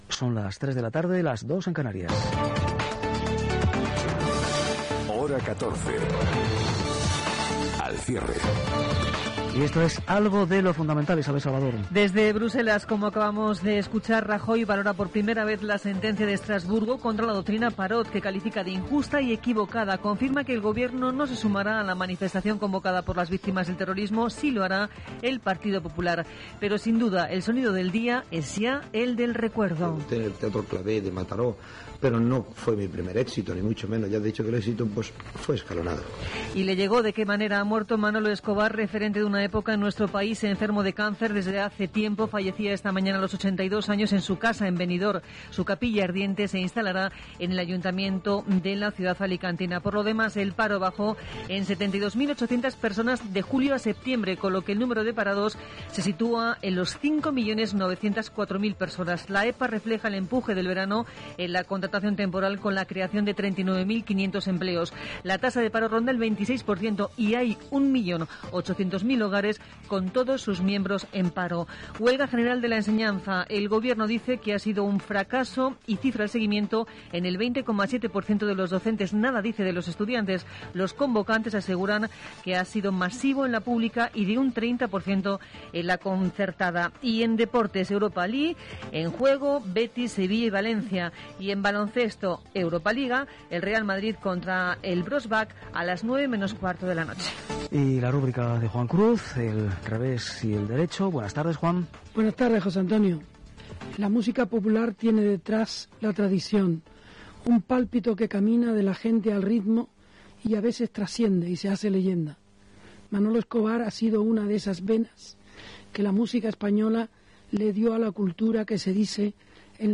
Hora, identificació del programa, manifestació contra el terrorisme, mort del cantant Manolo Escobar, baixa l'atur, vaga a l'ensenyament, partits de l'Europa League, opinió de Juan Cruz, publicitat, la borsa, premis Píncipe de Asturias, tema de Manolo Escobar, equip, indicatiu del programa
Informatiu